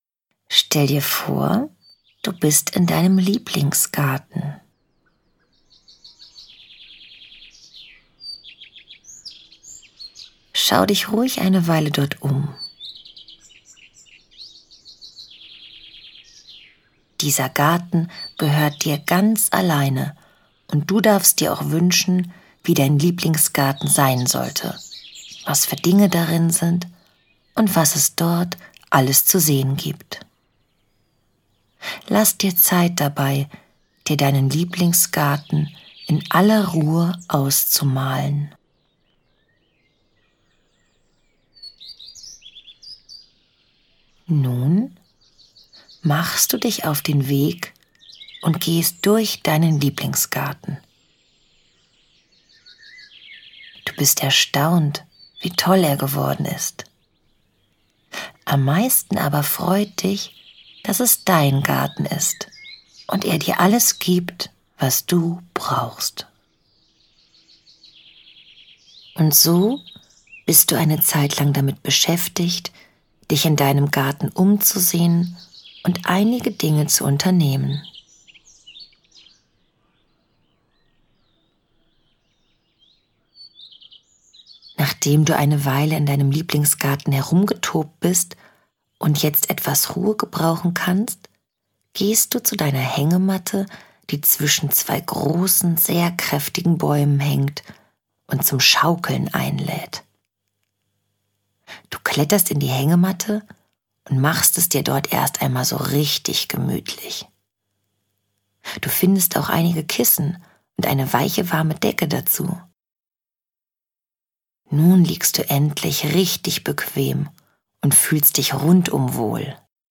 Hörbuch: FamilyFlow.